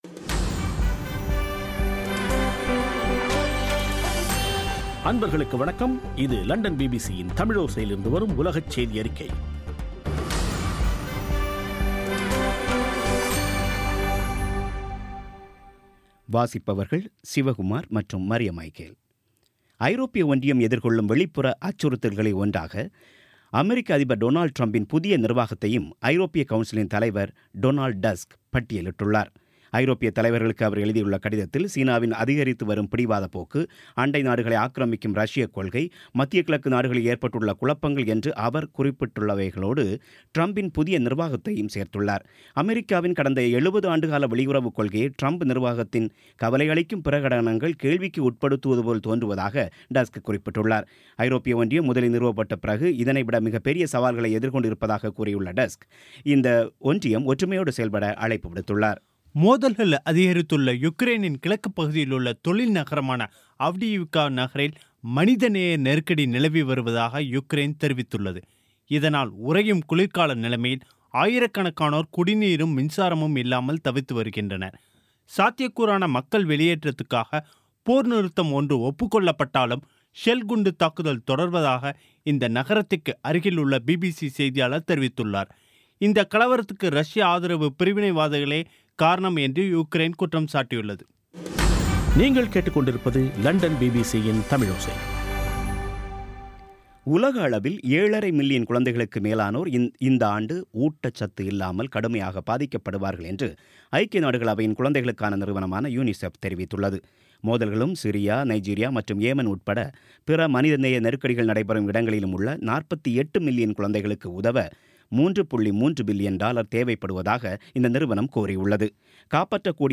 பிபிசி தமிழோசை செய்தியறிக்கை (31.01.17)